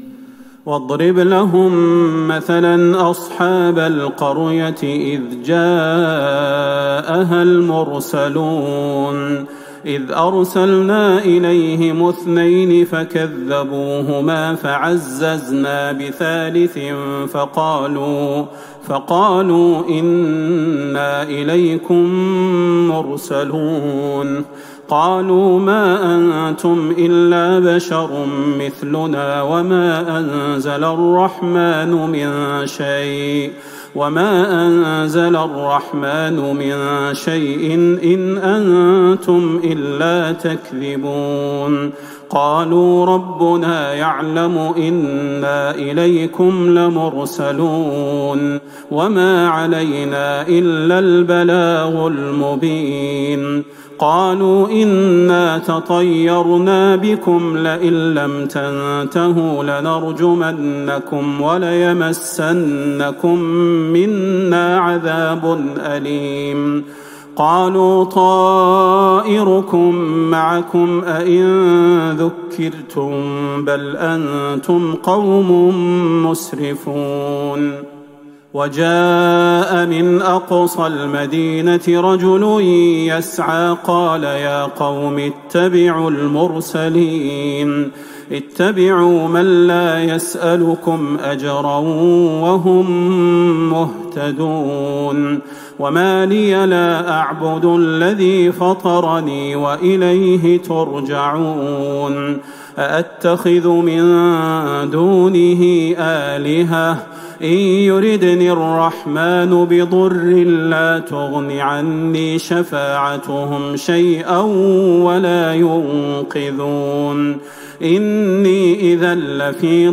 تراويح ٢٥ رمضان ١٤٤١هـ من سورة يسٓ { ١٣- النهاية } > تراويح الحرم النبوي عام 1441 🕌 > التراويح - تلاوات الحرمين